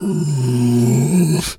bear_pain_whimper_12.wav